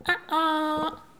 Les sons ont été découpés en morceaux exploitables. 2017-04-10 17:58:57 +02:00 206 KiB Raw History
oh-oh_02.wav